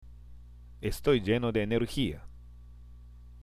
＜発音と日本語＞
（エストイ　ジェノ　デ　エネルヒア）